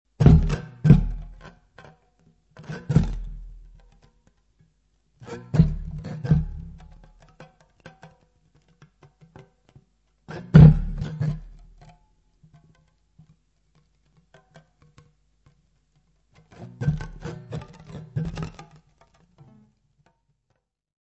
guitarra, voz.
: stereo; 12 cm
Music Category/Genre:  New Musical Tendencies